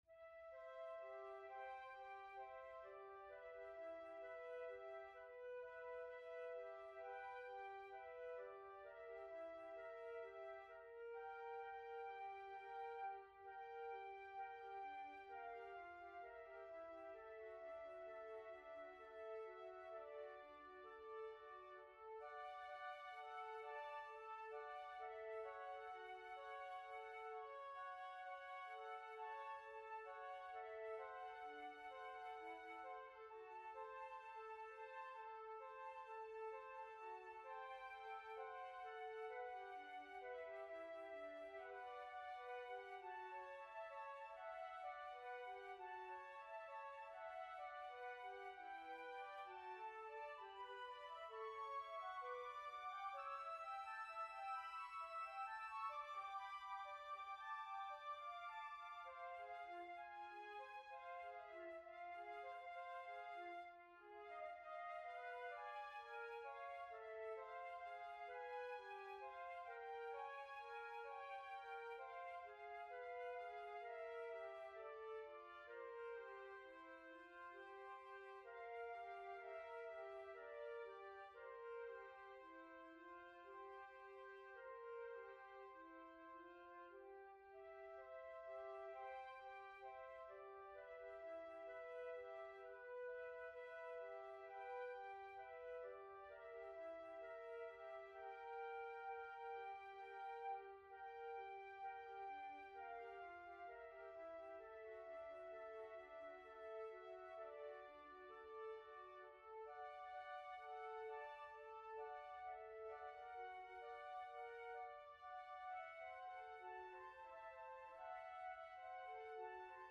Category: Flutes